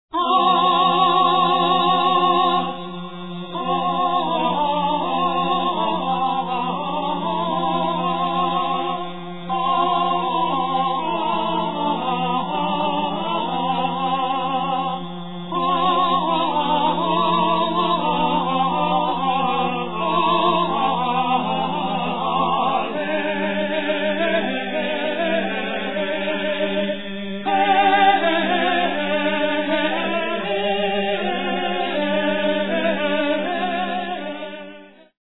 countertenor
tenors
viol
organum for 3 voices